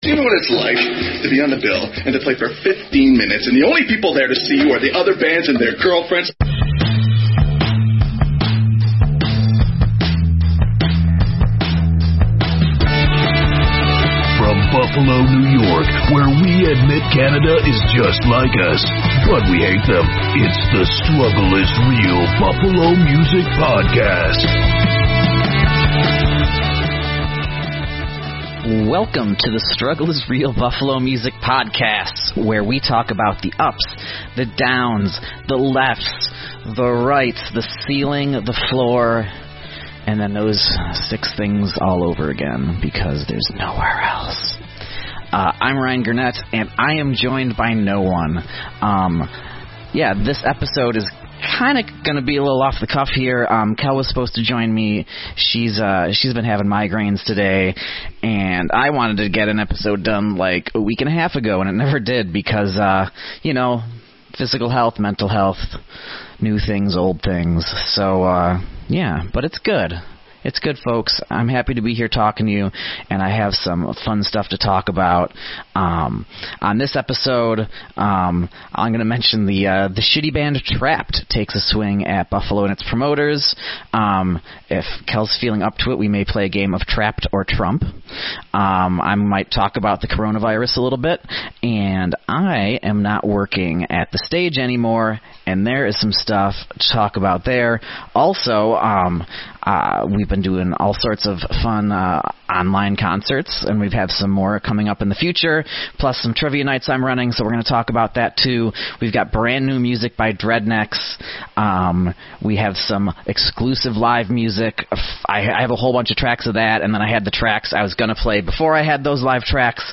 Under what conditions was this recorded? live Zoom performance on Zoom